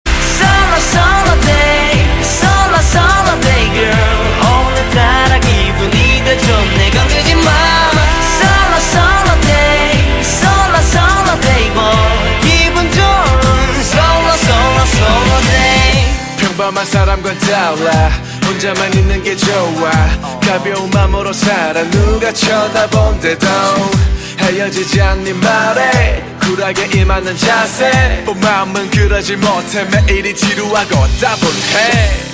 M4R铃声, MP3铃声, 日韩歌曲 93 首发日期：2018-05-15 20:34 星期二